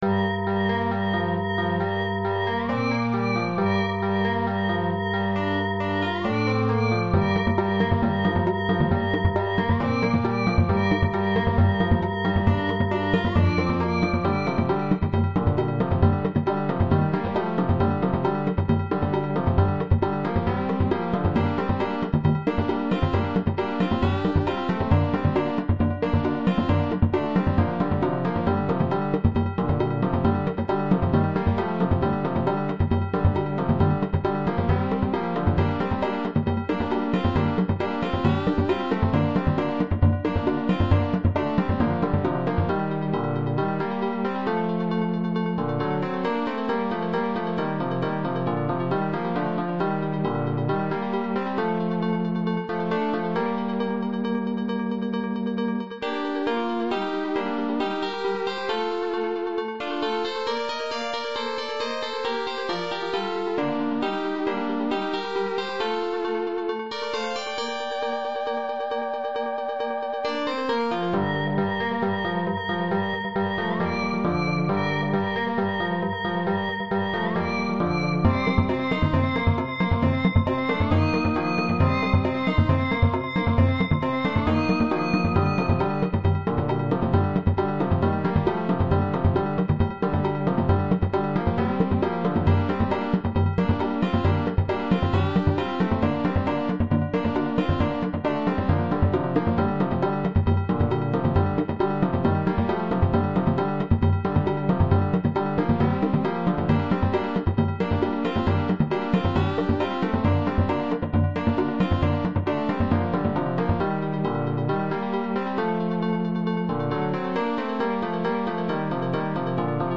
Moccasinian_March.mp3